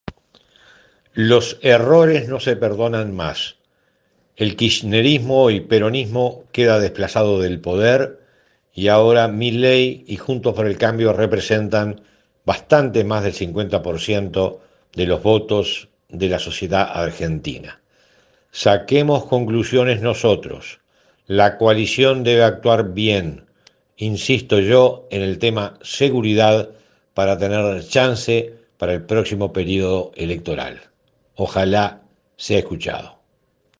Escuche las declaraciones de Zubia: